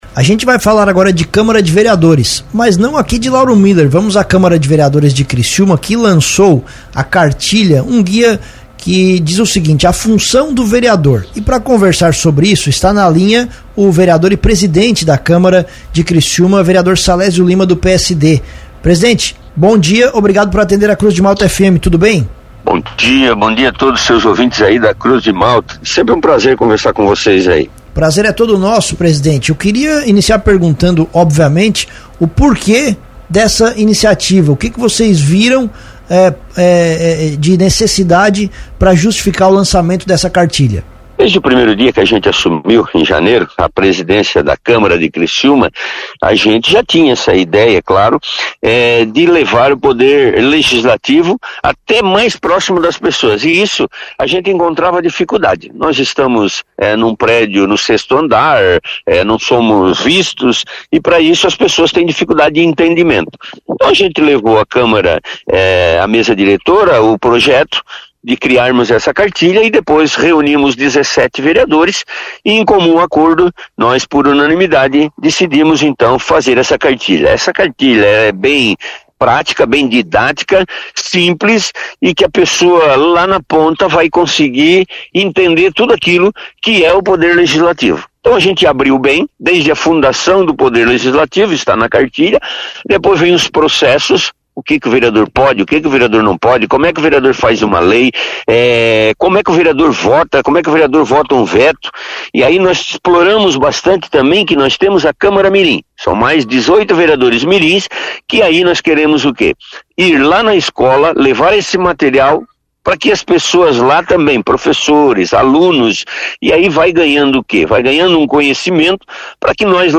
Durante entrevista ao Cruz de Malta Notícias desta quinta-feira (21), o presidente Salésio Lima (PSD) comentou sobre a iniciativa.
21.09-ENTRE-SALESIO-LIMA.mp3